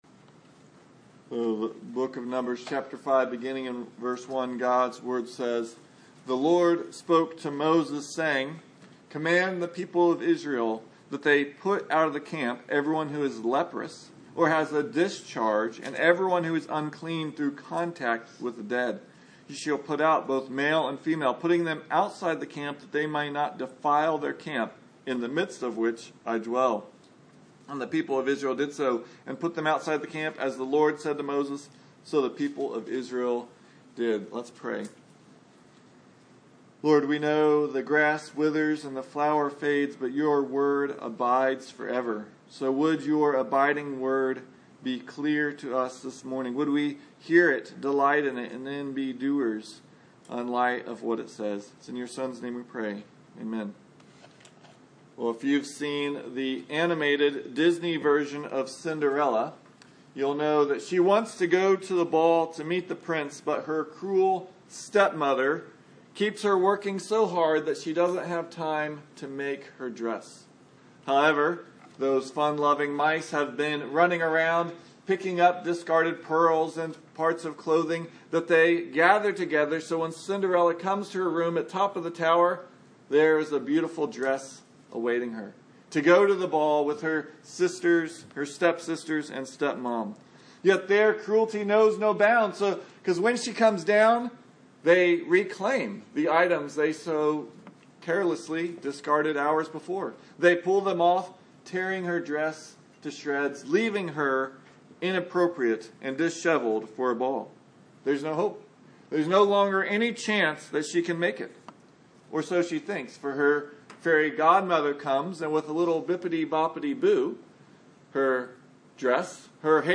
This sermon begins a look at several passages that deal with purity within the camp and drawing near to a holy God.
Service Type: Sunday Morning